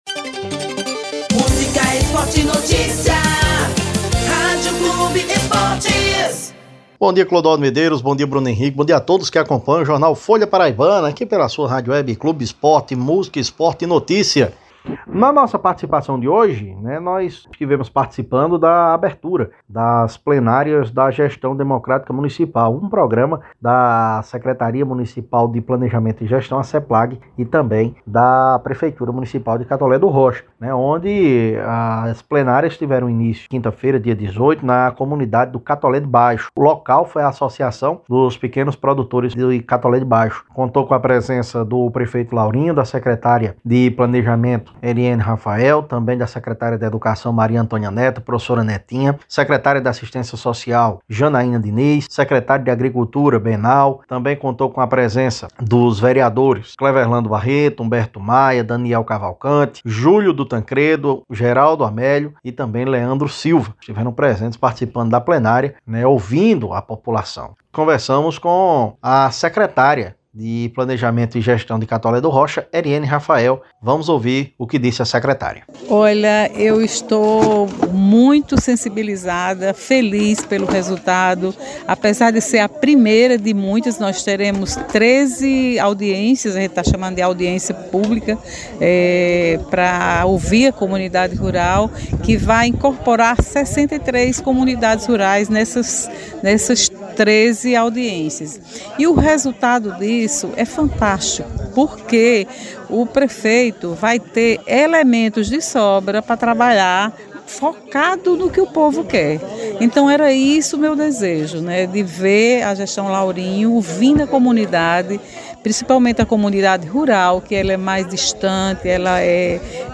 entrevista o Prefeito Laurinho Maia e a Secretária de Planejamento Eriene Rafael sobre o Projeto “Gestão Democrática Municipal”.